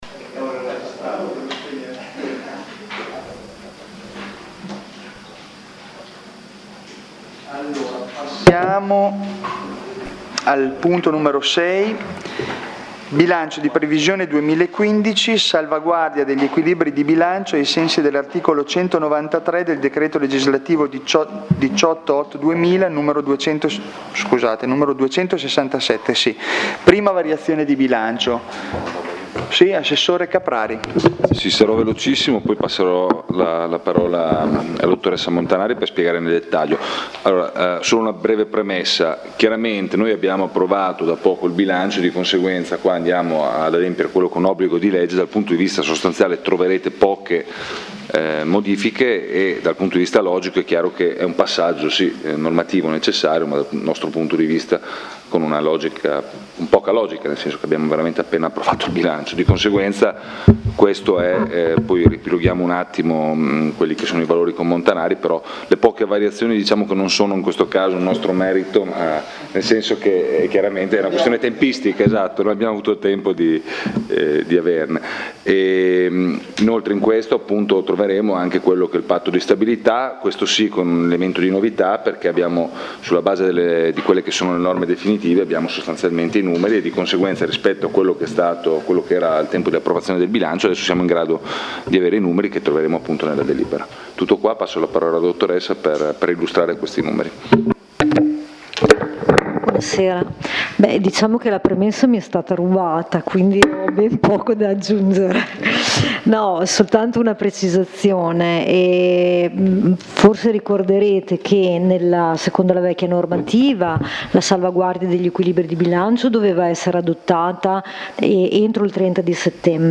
Consigli Comunali – 2015